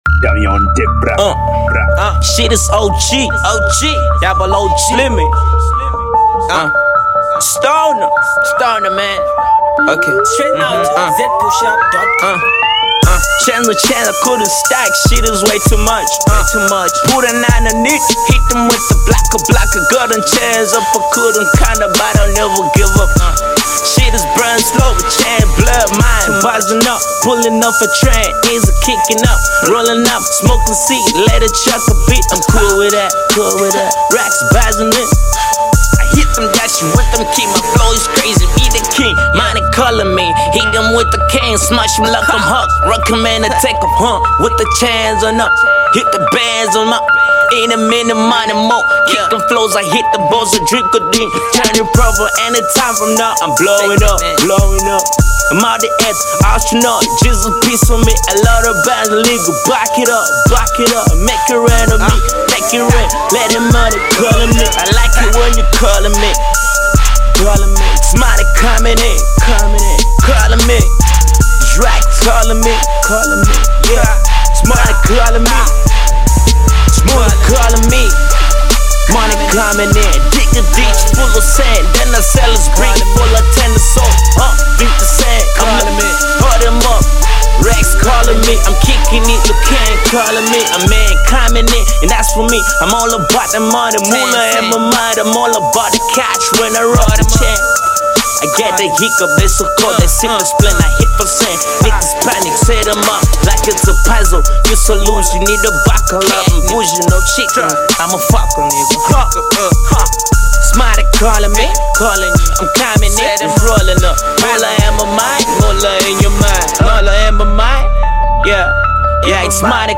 dope trap joint
Download and vibe to some hot bars